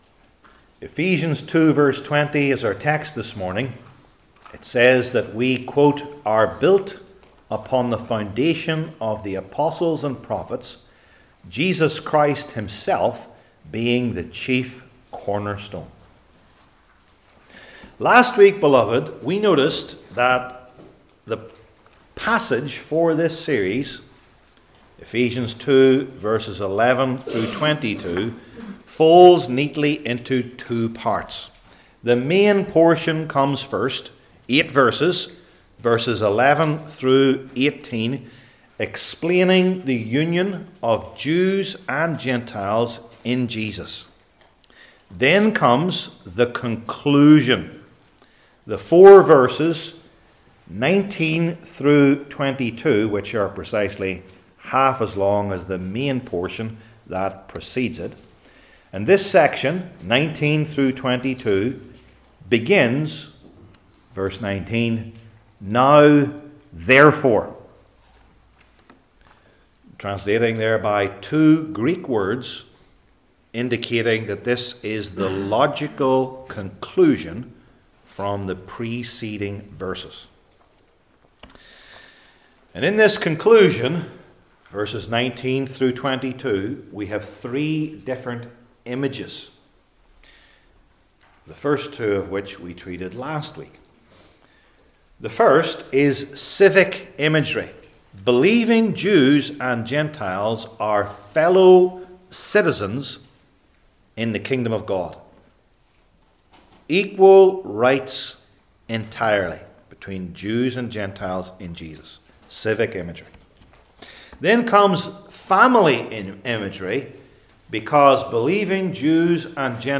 Ephesians 2:20 Service Type: New Testament Sermon Series I. Exposition II.